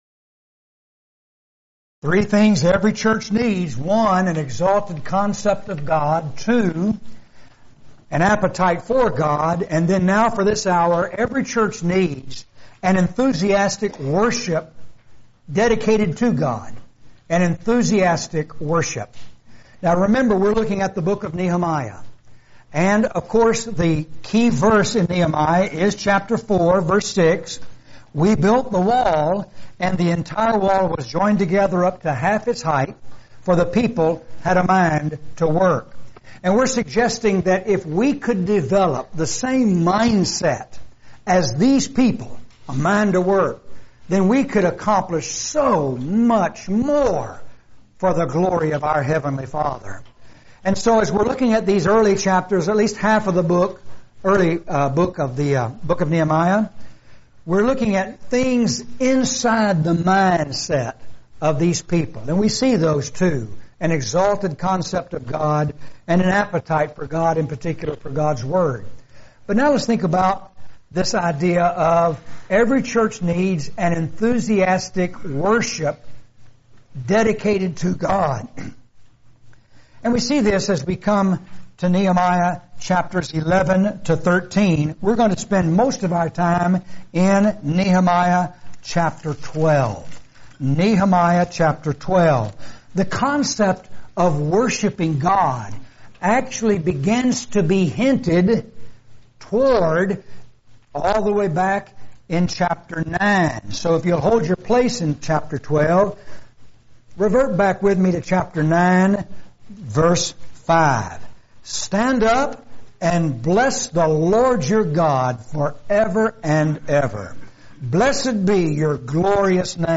Preacher's Workshop